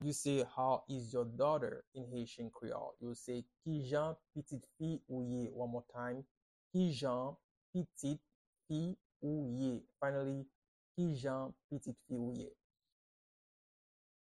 Pronunciation and Transcript:
How-is-your-daughter-in-Haitian-free-Creole-–-Kijan-pitit-fi-ou-ye-pronunciation-by-a-Haitian-teacher.mp3